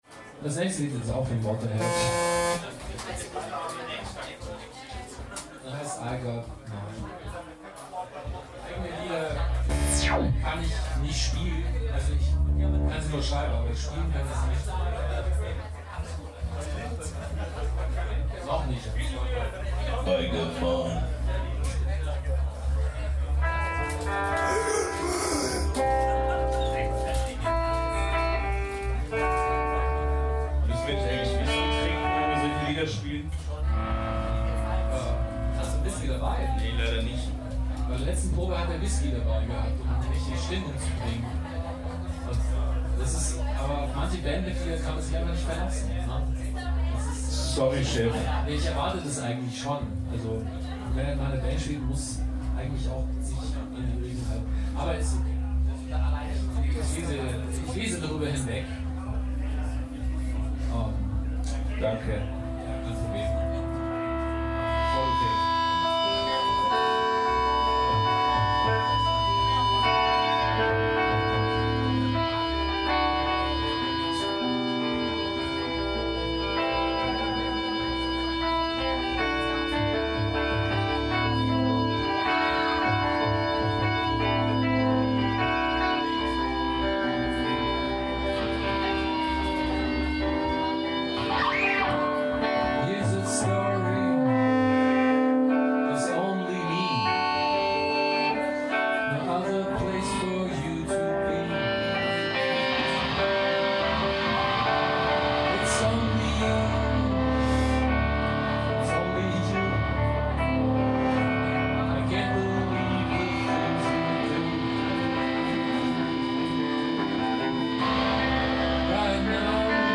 21.12.13 beim Bodensatzfestival, München
rgit + voc